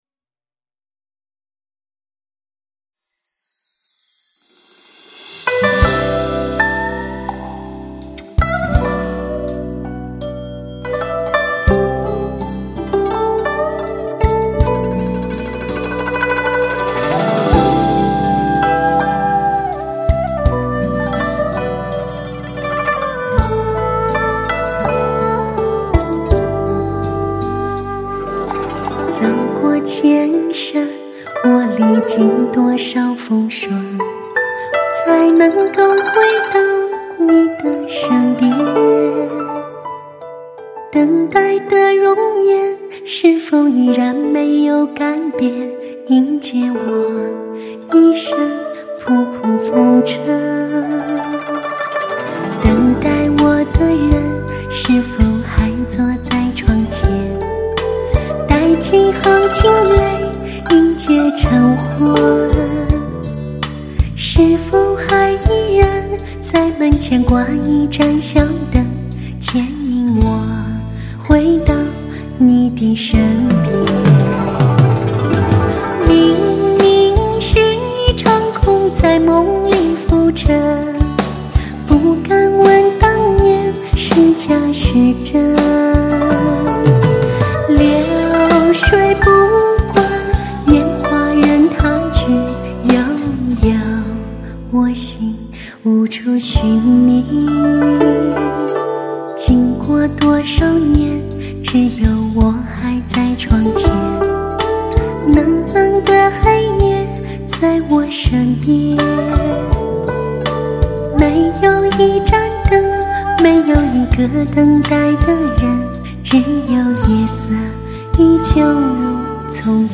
史上人声最甜美感情最丰富的女声
经典HI-END试音王，国际发烧音响协会权威推荐的专业测试盘